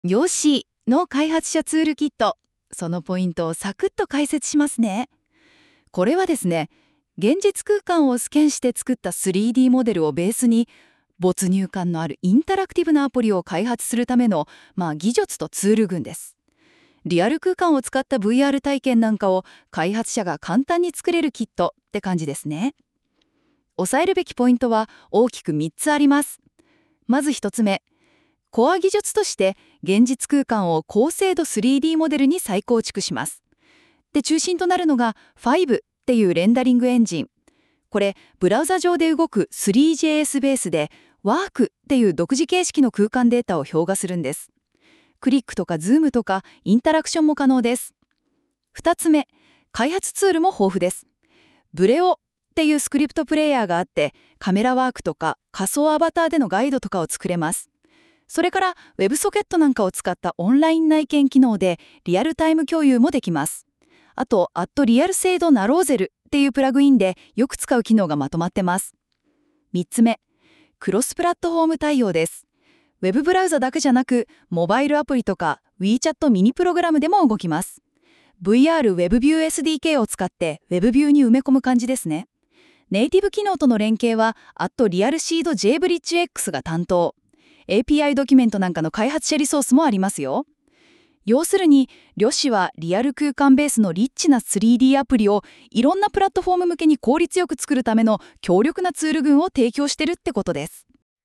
音声解説